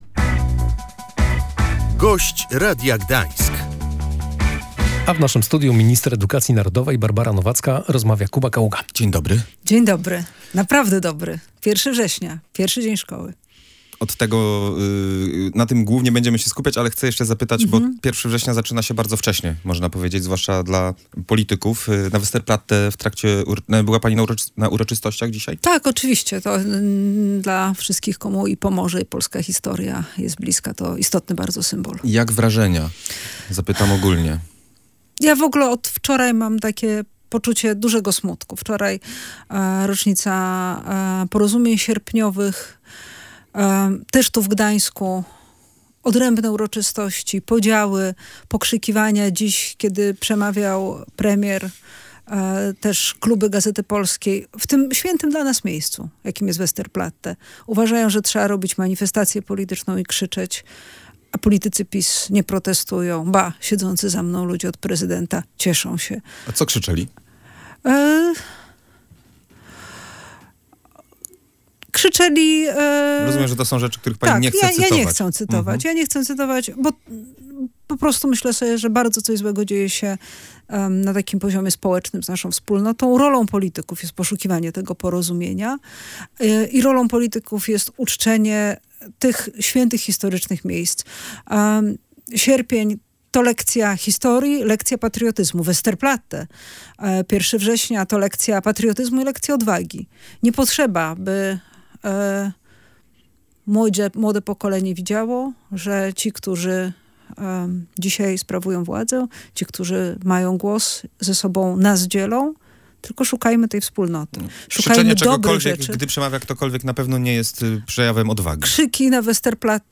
Barbara Nowacka, minister edukacji narodowej, przekonywała w Radiu Gdańsk